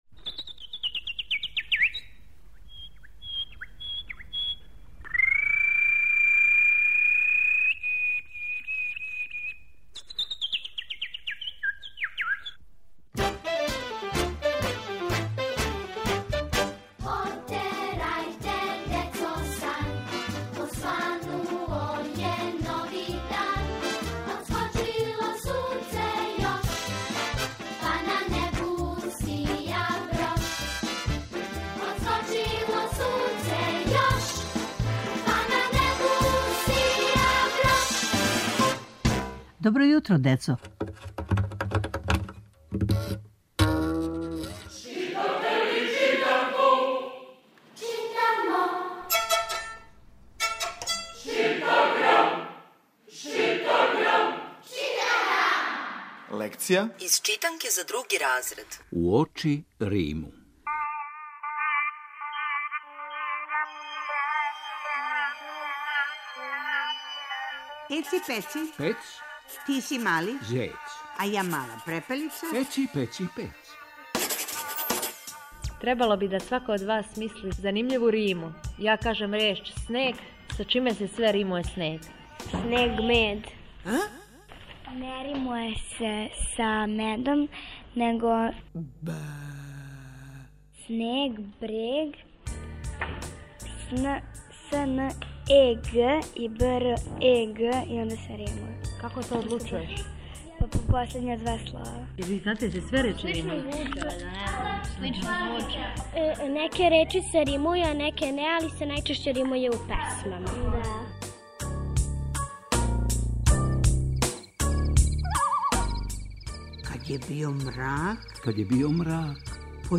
Ево њене приче "Калеидоскоп" коју емитујемо у наставцима. Слушате први део.